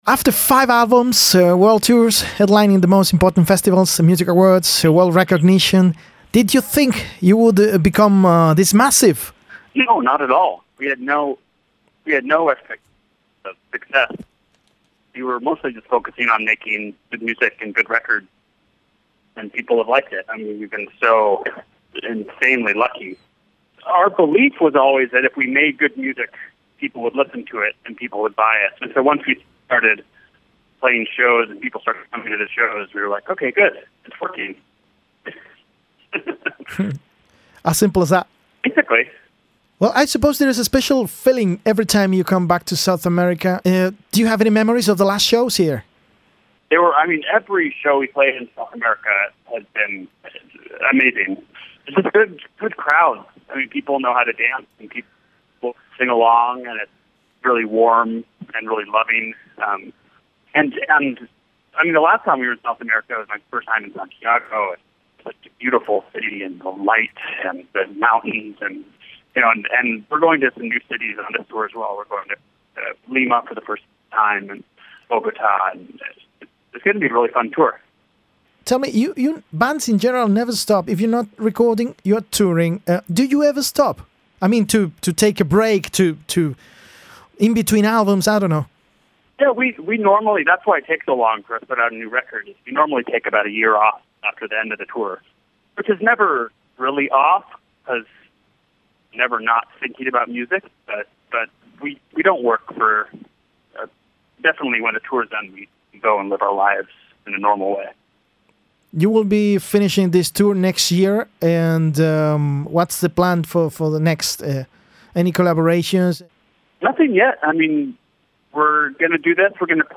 phoner-arcade-fire-edit